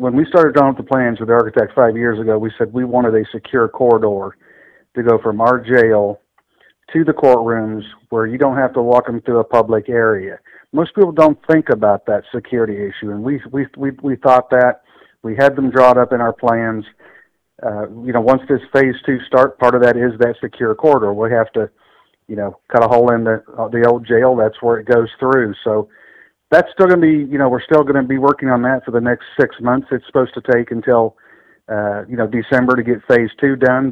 According to Johnson, this also drastically enhances safety and security.